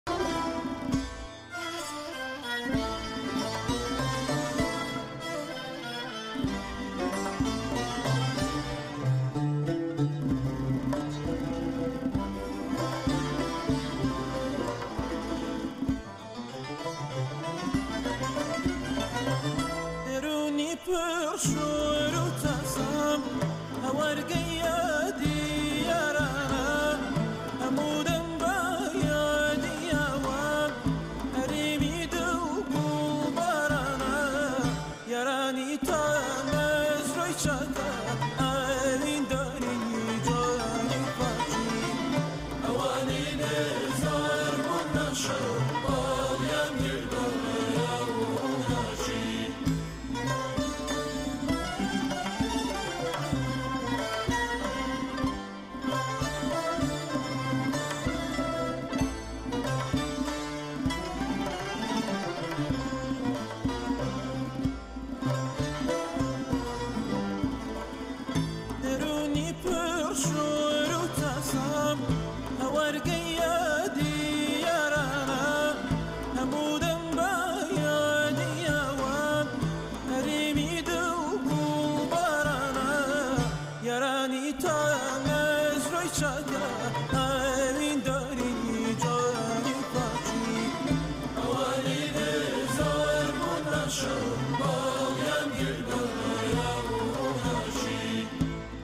گۆرانییەکی کوردی